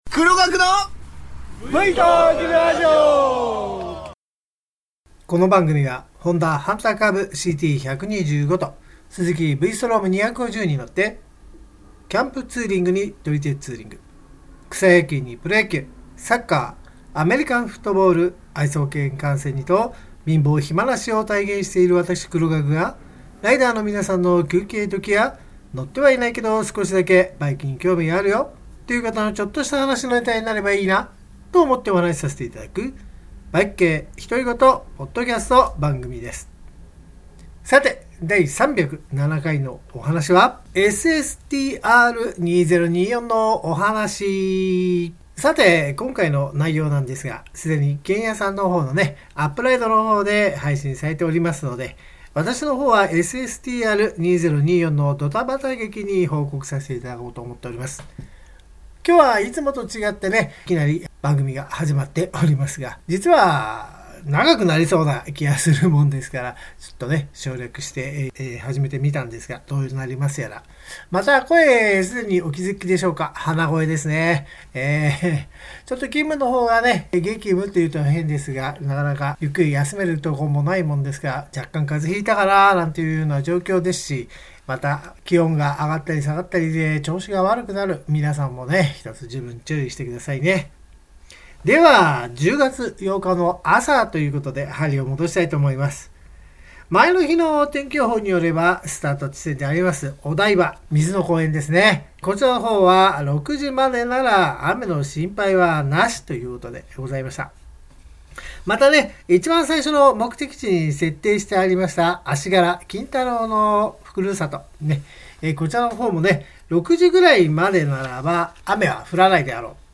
バイク系独り言ポッドキャスト番組 Vトーク radio